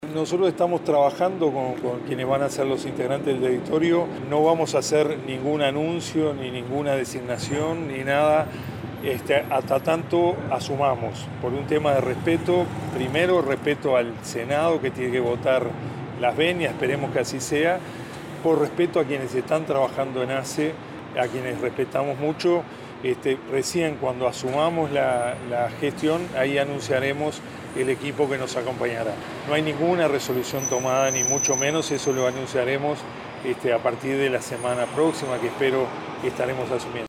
El doctor Marcos Carámbula habló en conferencia de prensa y dijo que no hará ningún nombramiento en ASSE hasta que la próxima semana asuma el cargo de presidente.